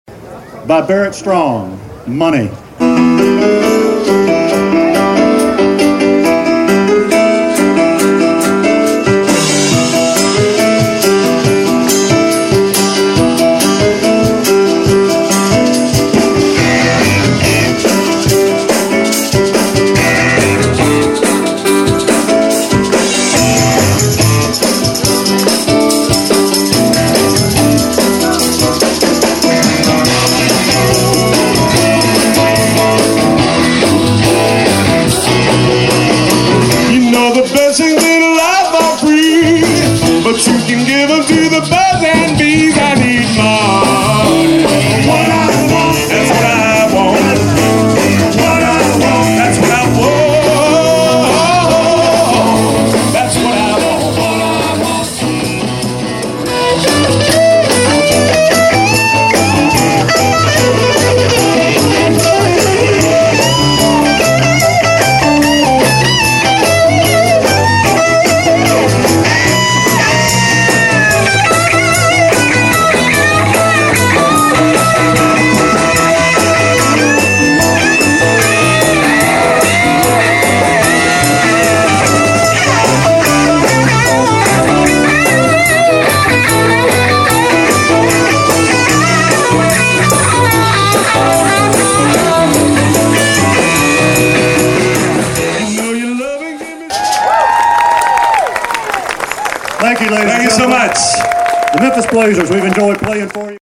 Live at Shangri-la